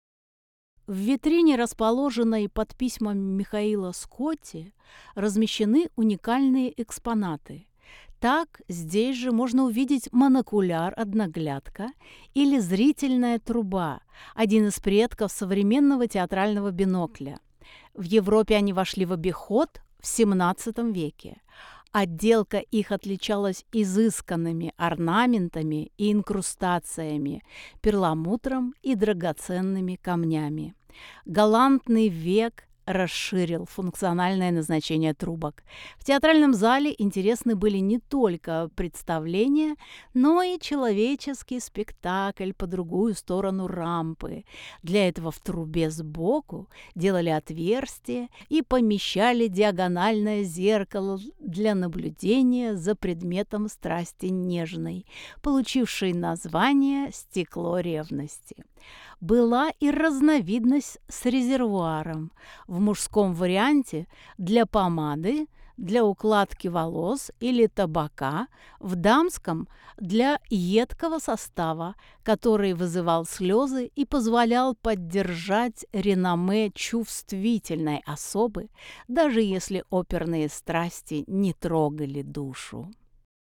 Тифлокомментарии к экспонатам выставки
Аудиогид. 2 этаж. 2 зал. Монокуляр Аудиогид. 2 этаж. 3 зал.